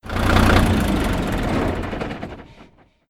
engine_off.mp3